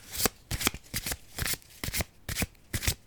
walkingsoundmaybe.ogg